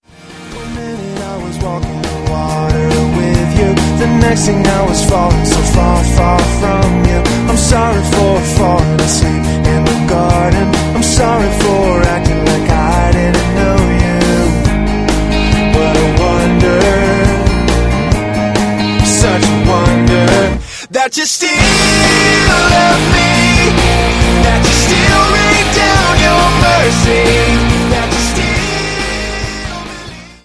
explosiven, treibenden Rockstücken
ultra-eingängigen Pop/Rock.
• Sachgebiet: Pop